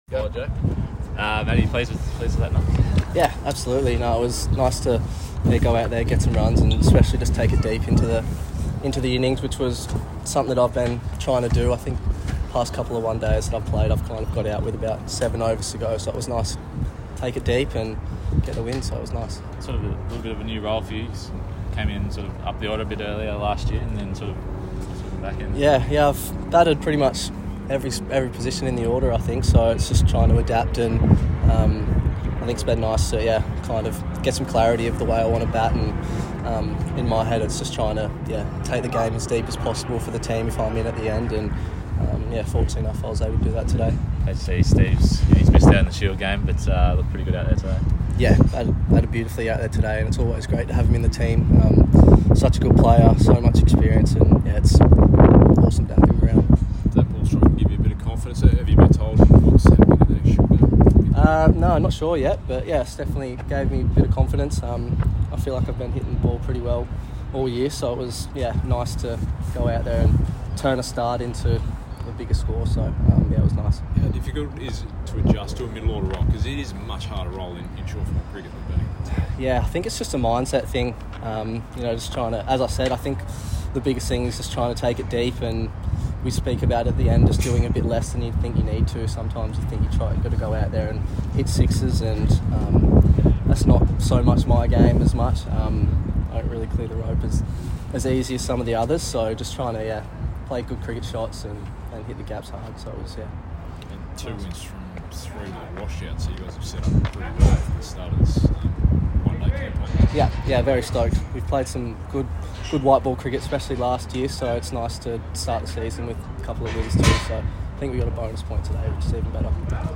Matthew Gilkes' 74 Off 42 secures Player of the Match in New South Wales' 140-run victory – Post-match interview